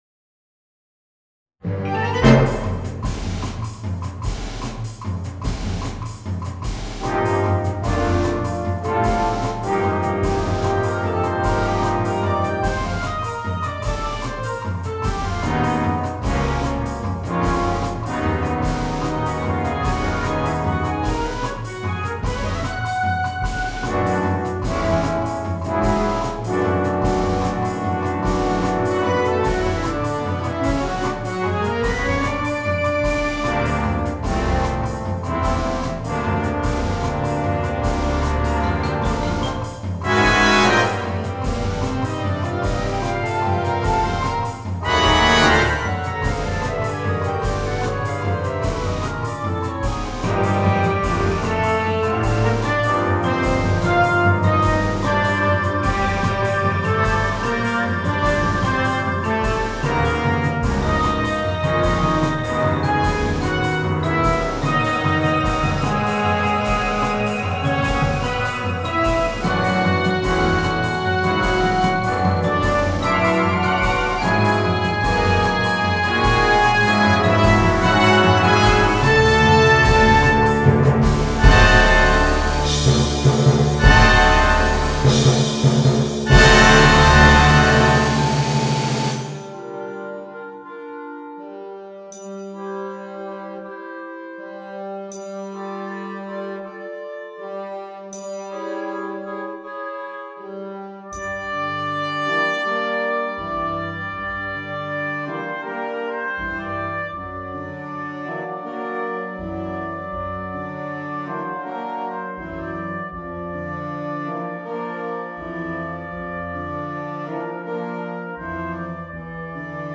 • Piccolo
• Oboe
• Fagot
• Clarinete Bajo
• Saxofón Barítono
• Tuba
• Xilófono
· Capachos
Campanas Tubulares